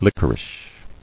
[lick·er·ish]